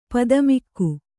♪ padamikku